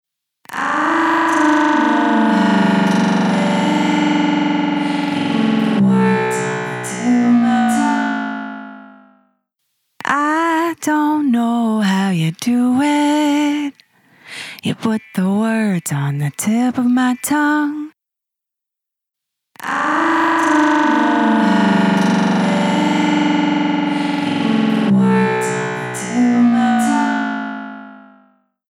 H910 Harmonizer | Vocals | Preset: Robot Voice
H910-Harmonizer-Eventide-Vocals-Robot-Voice.mp3